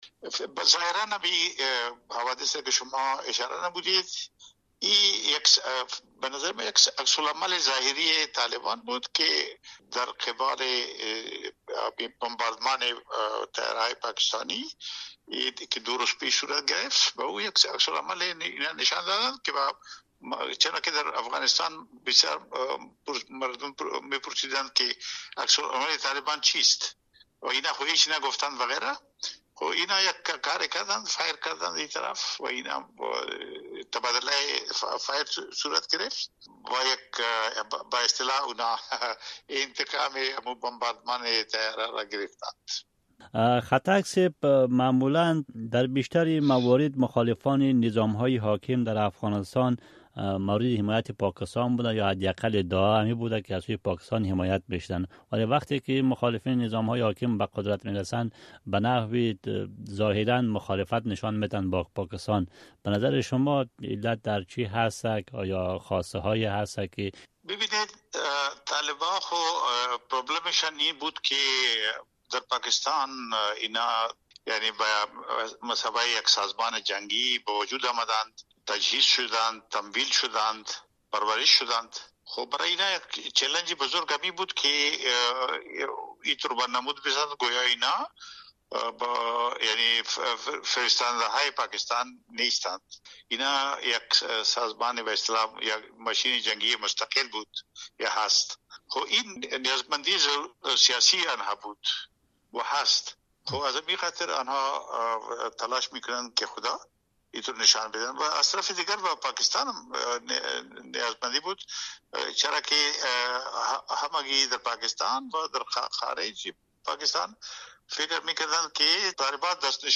مصاحبه - صدا
افراسیاب ختک، عضو پیشین مجلس سنای پاکستان